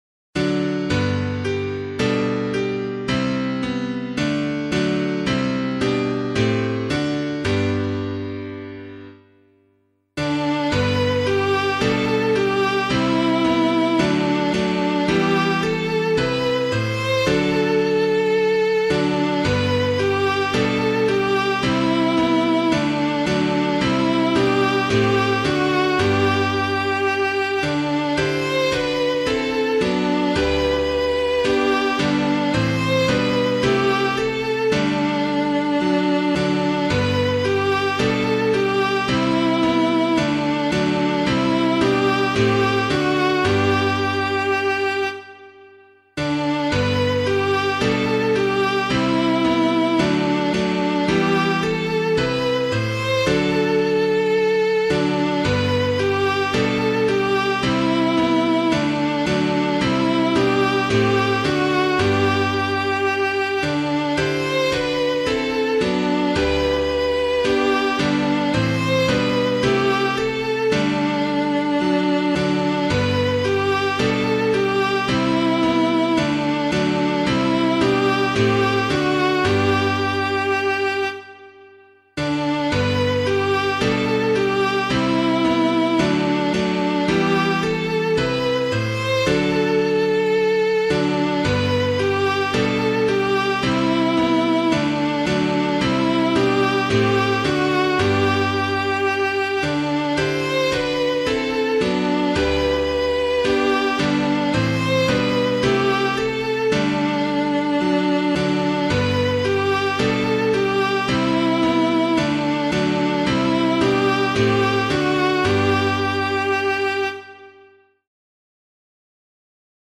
Hymn suitable for Catholic liturgy